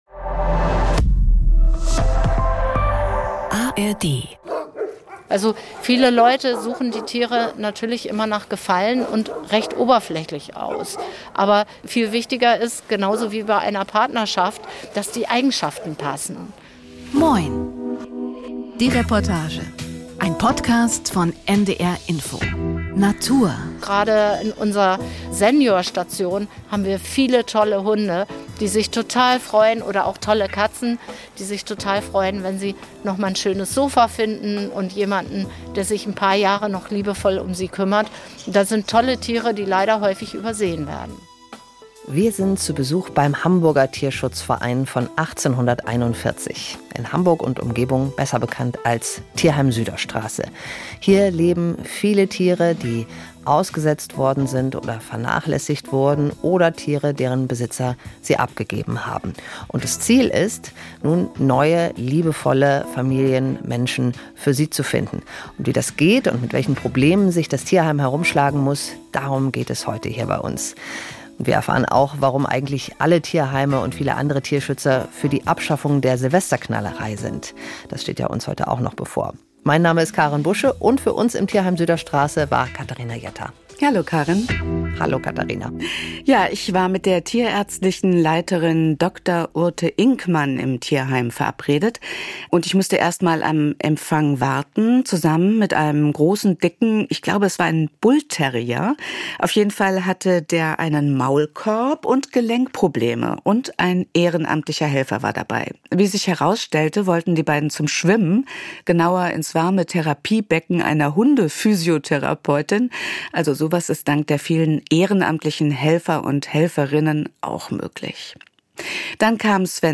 „Moin!“ – Die Reportage verwebt lockere Gespräche mit O-Ton-Szenen und viel Atmosphäre.